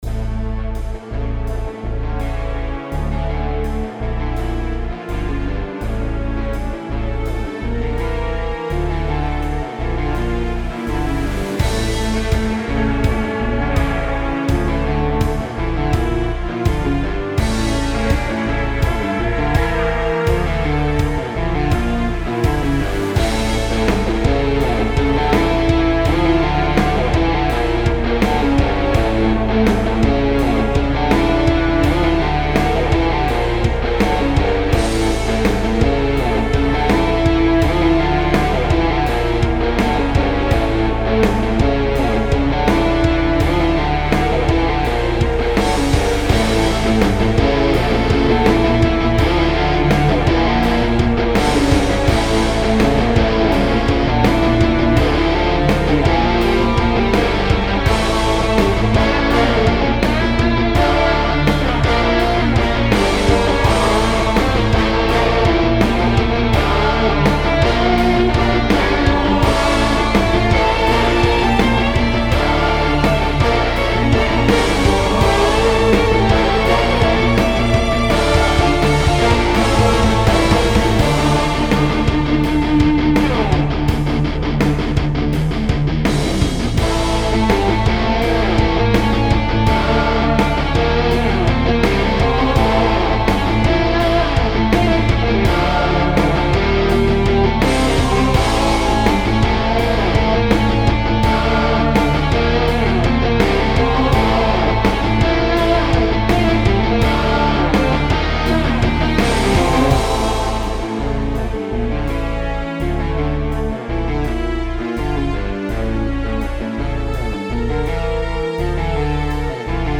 Metal music